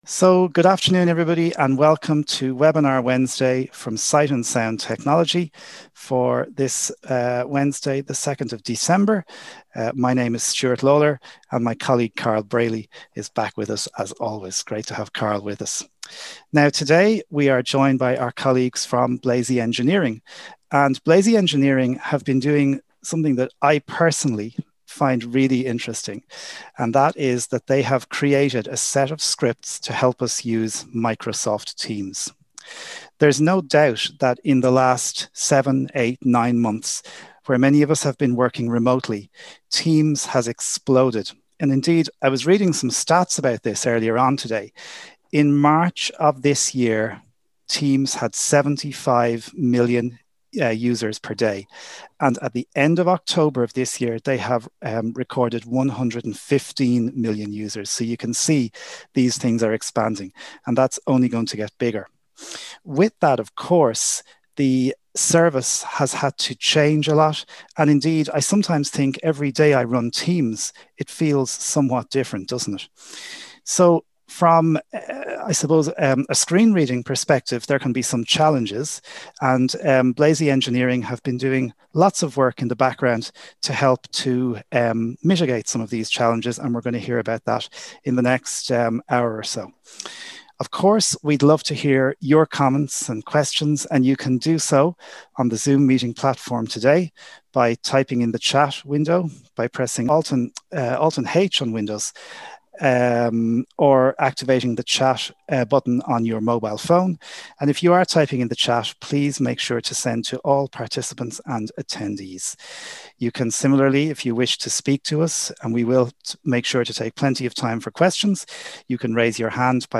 Webinar: Blazie Engineering and their Microsoft Teams JAWS scripts